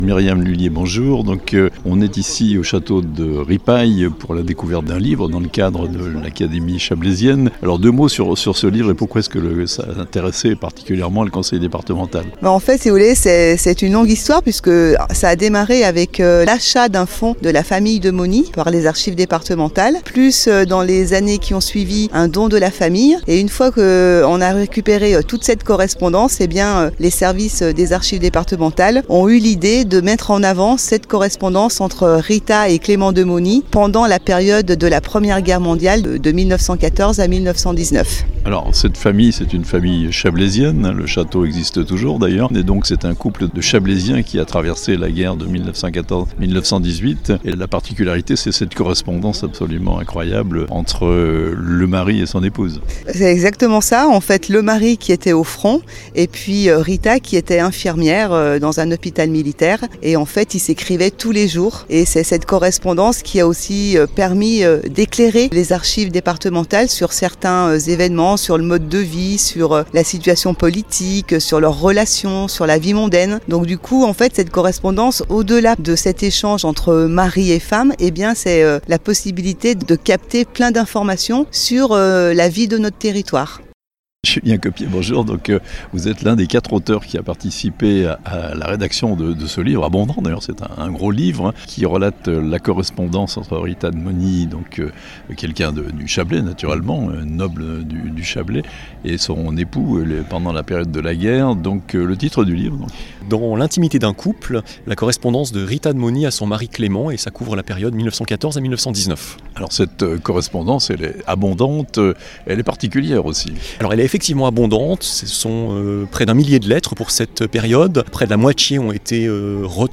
Un livre coédité par le Département 74 présenté au château de Ripaille (interviews)